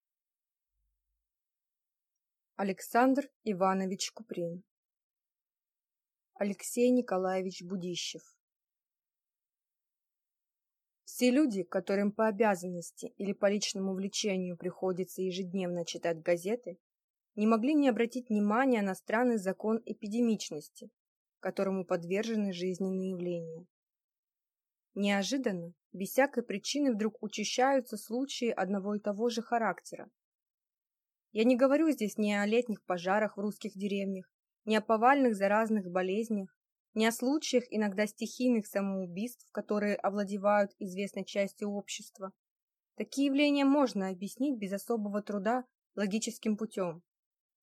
Аудиокнига А. Н. Будищев | Библиотека аудиокниг
Прослушать и бесплатно скачать фрагмент аудиокниги